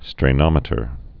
(strā-nŏmĭ-tər)